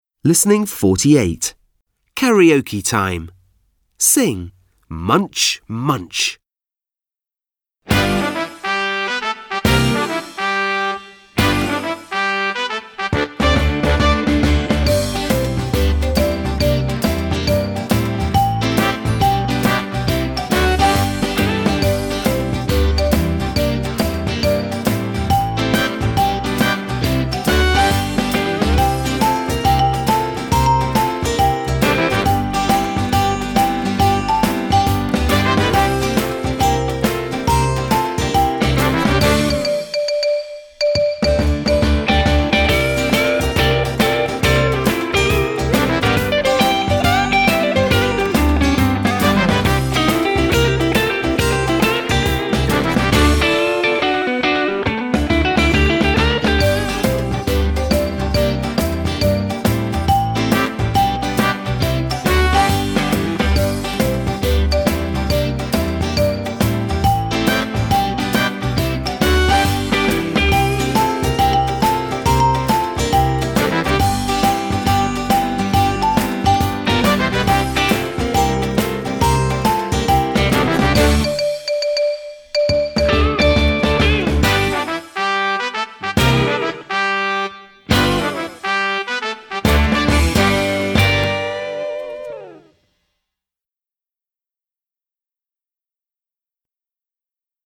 w wersji karaoke (tylko linia melodyczna)